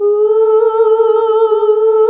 UUUUH   A.wav